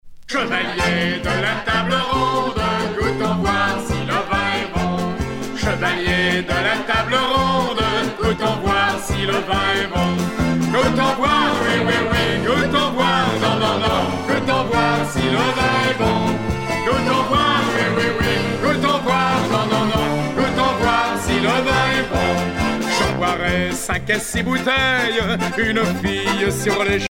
Fonction d'après l'analyste danse : marche
circonstance : bachique
Genre strophique
Catégorie Pièce musicale éditée